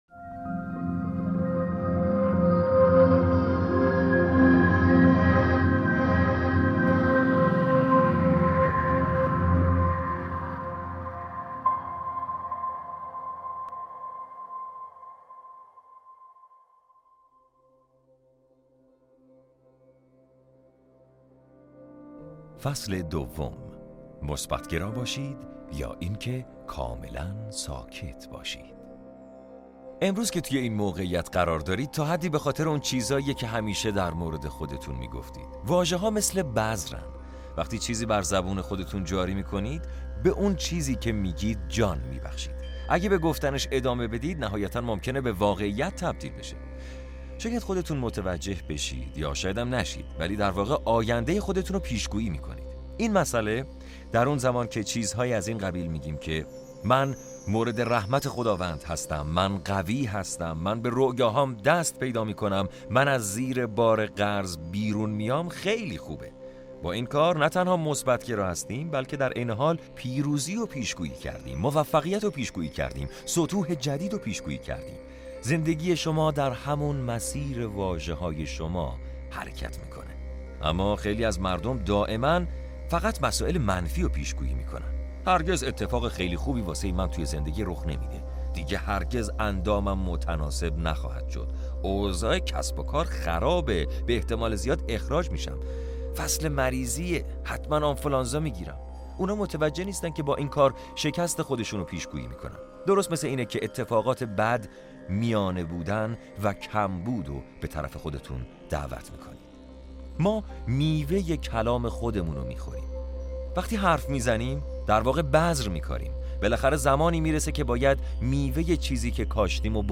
فصل دوم کتاب صوتی من قدرت هستم/ مثبت گرا باشید!
صد آنلاین | پادکست رایگان کتاب صوتی قدرت من هستم به صورت کامل درباره قدرت کلام و